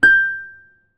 ZITHER G 4.wav